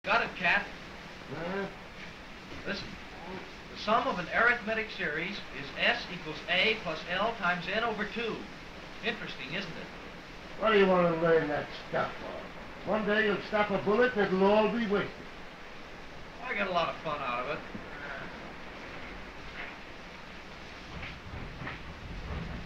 In the movie "All quiet on the Western front" from 1930 (which is an adaptation of the 1929 novel by Erich Maria Remarque), there is a scene where a soldier at the front mentions the sum of arithmetic series formula.